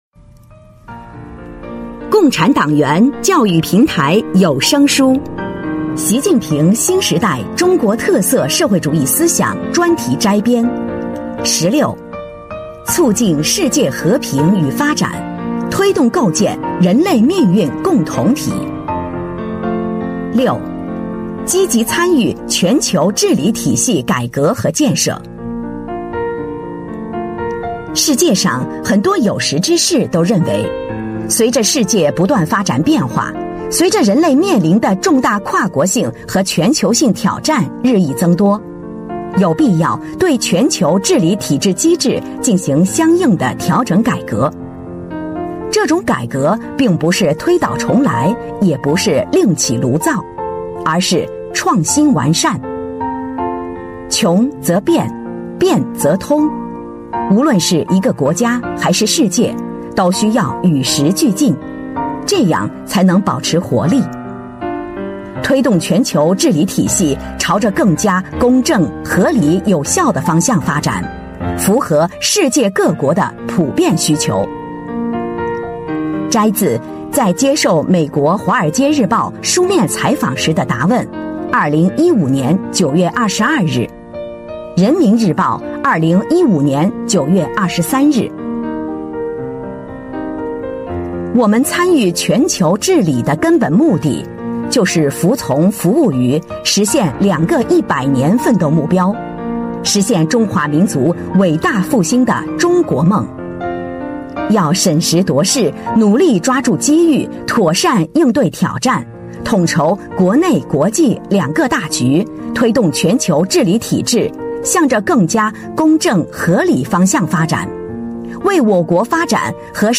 聊城机关党建 - 《习近平新时代中国特色社会主义思想专题摘编》 - 主题教育有声书 《习近平新时代中国特色社会主义思想专题摘编》（77）